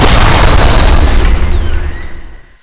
explode_2.wav